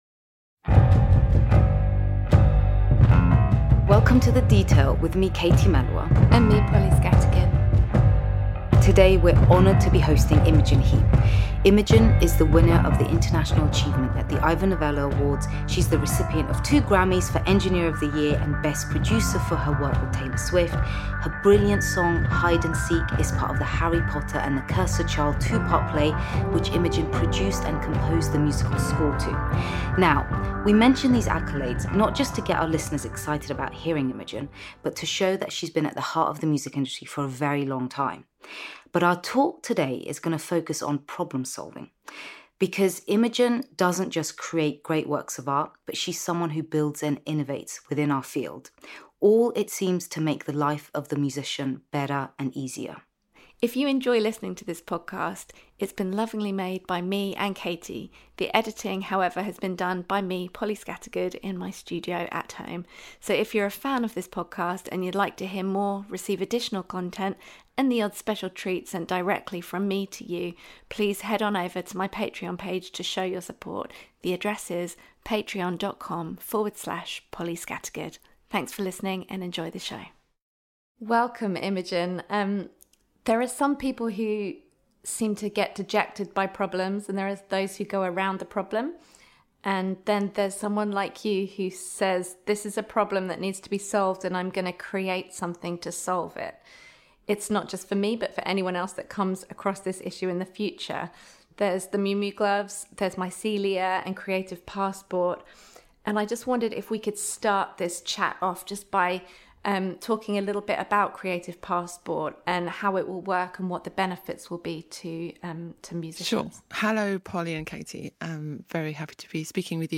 A podcast by Polly Scattergood and Katie Melua, chatting with Special Guest, Pioneering Musician, Songwriter, and Producer Imogen Heap.